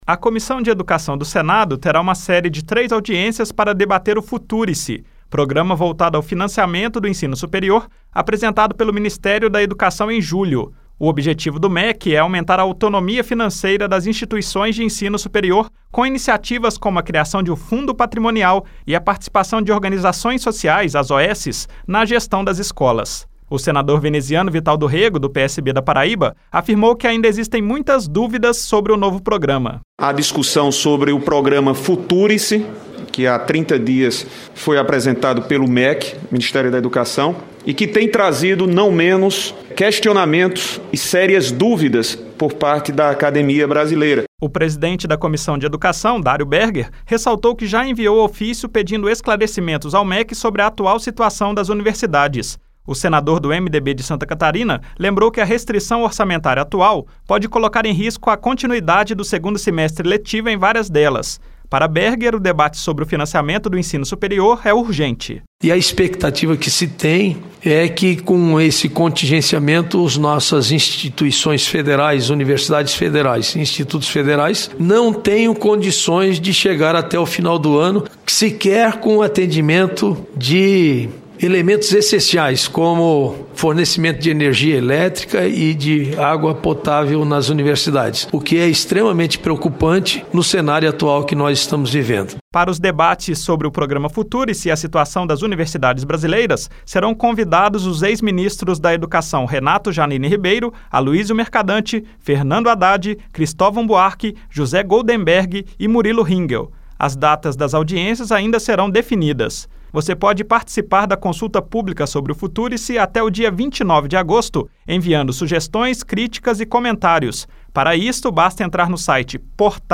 A Comissão de Educação do Senado vai convidar ex-ministros da área para debater o programa Future-se. Para o Senador Veneziano Vital do Rêgo (PSB-PB), ainda existem muitas dúvidas sobre o novo programa de financiamento do ensino superior.